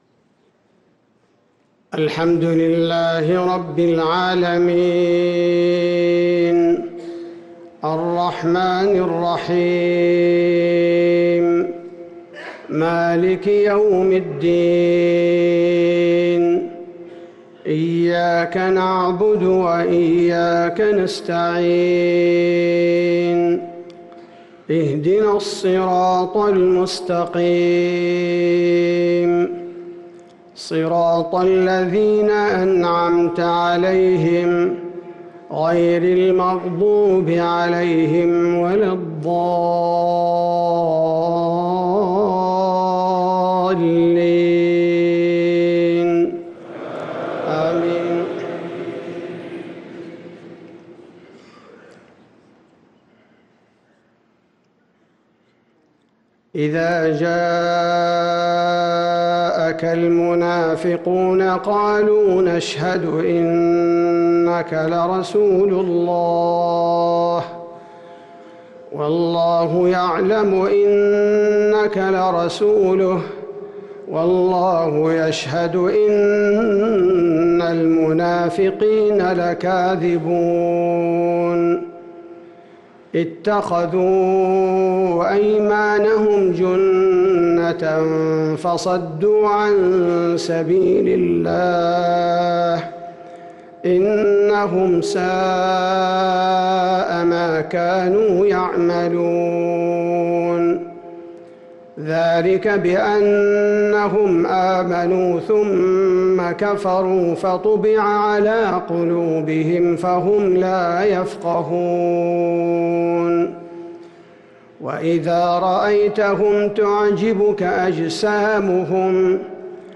صلاة الفجر للقارئ عبدالباري الثبيتي 11 شوال 1444 هـ